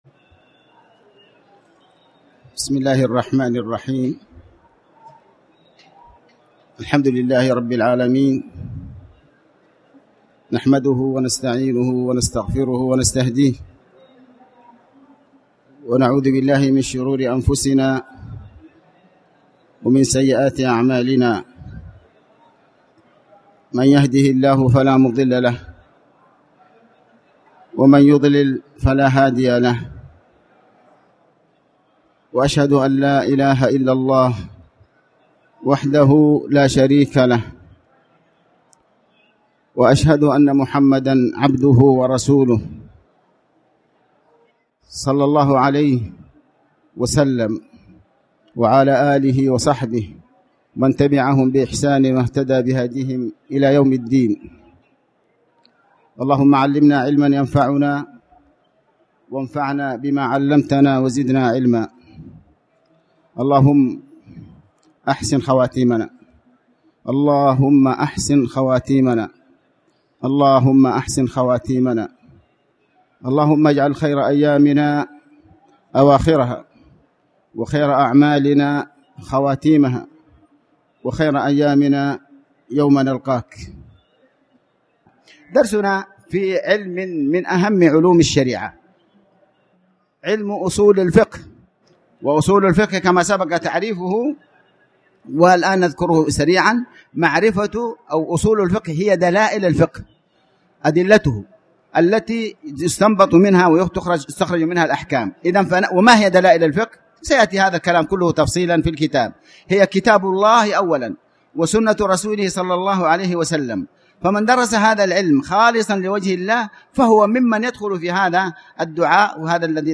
تاريخ النشر ١٤ محرم ١٤٣٩ هـ المكان: المسجد الحرام الشيخ: علي بن عباس الحكمي علي بن عباس الحكمي تعريف أصول الفقه